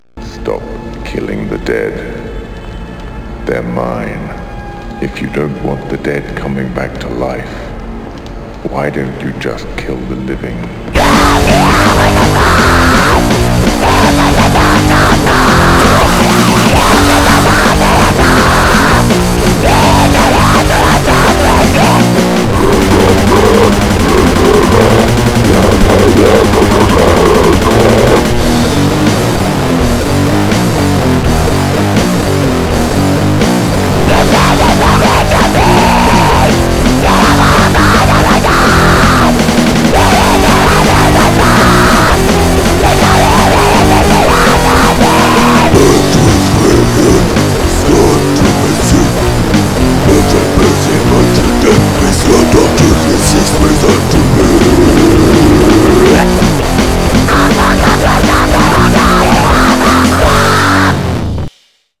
projet solo grind
Passage guttural entre 0'46 et 0'56 sec.